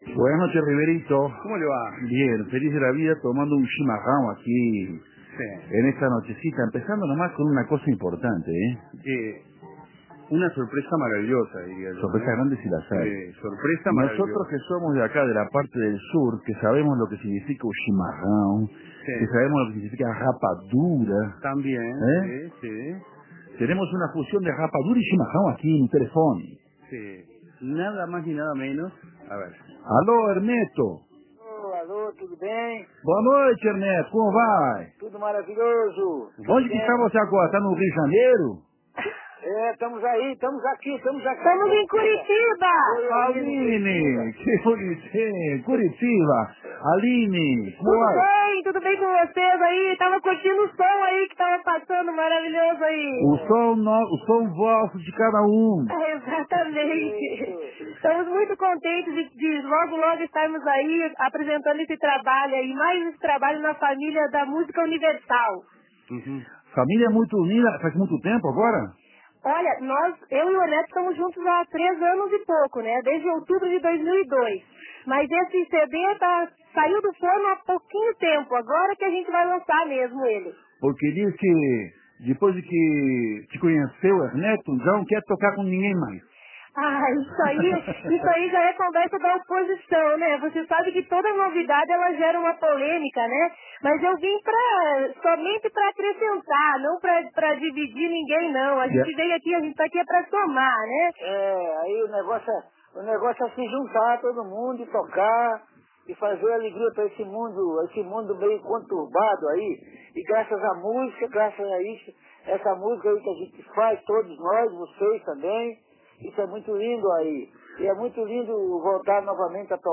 Charla telefónica -con yapa incluida- previa al show en el Solís
Entrevista